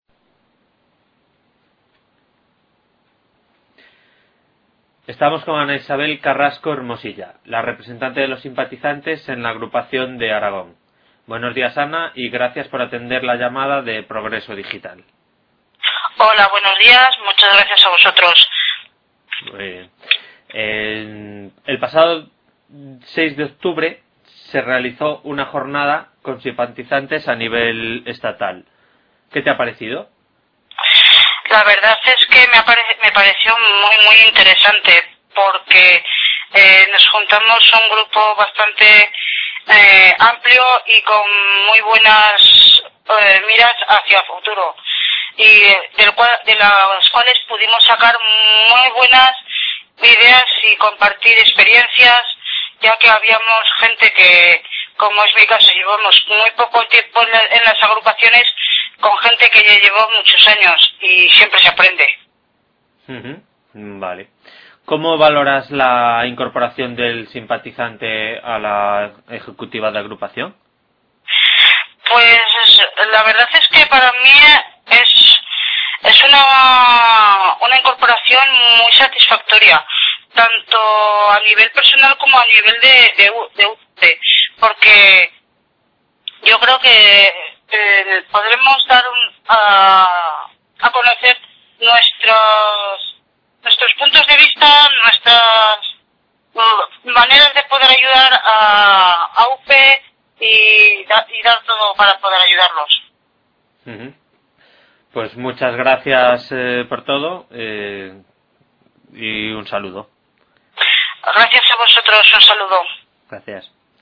A continuación, podéis escuchar a tres simpatizantes y de su voz un resumen de dichas jornadas y de las cuestiones de futuro sobre las que quiere incidir este colectivo: